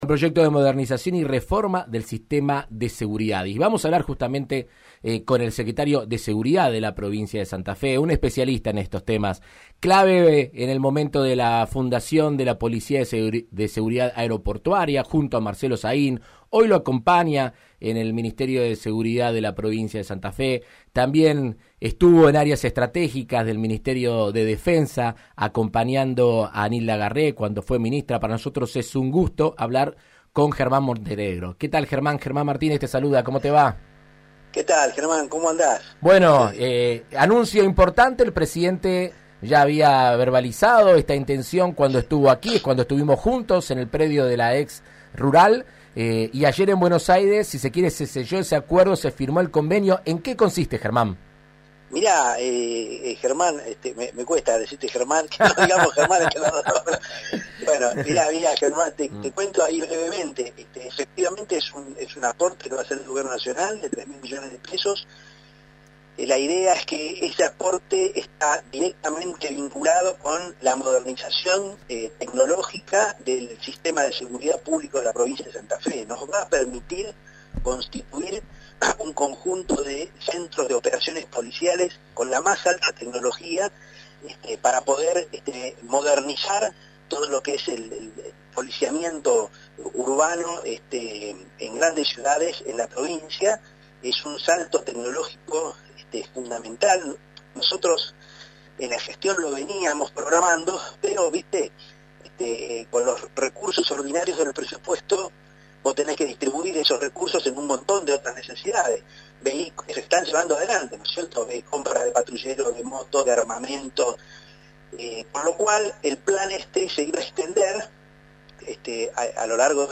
En Argentina Unida contra el Coronavirus, el diputado nacional Germán Martínez habló al respecto con el secretario de seguridad de Santa Fe, Germán Montenegro.